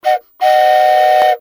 whistle_notice.ogg